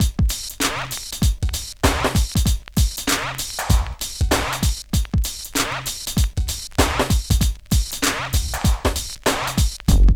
112 DRM LP-L.wav